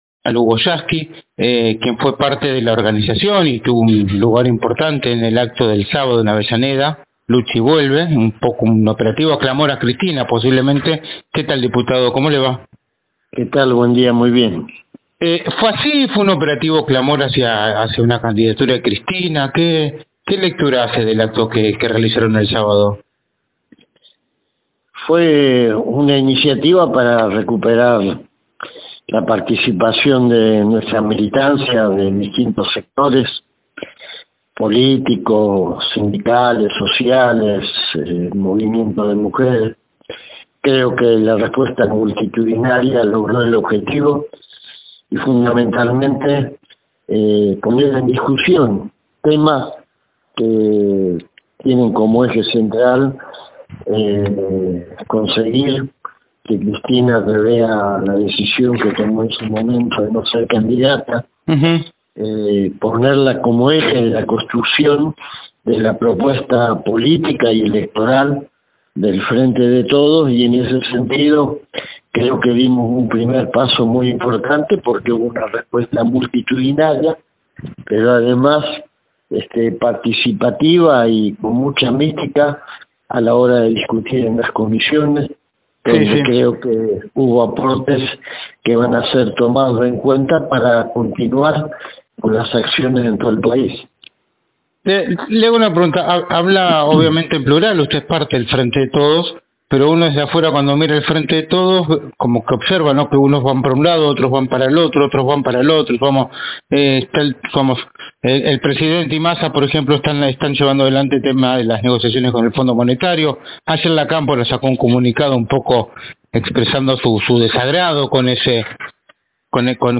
El diputado nacional por el Frente de Todos, Hugo Yasky, habló en exclusiva con NorteOnline y se refirió al multitudinario acto “Luche y Vuelve”, en homenaje a la vicepresidenta de la Nación.